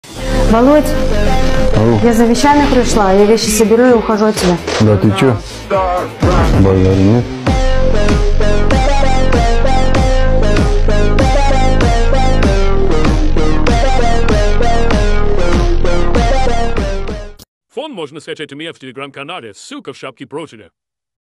Мемный звук из тик тока да ты чё базару нет оригинал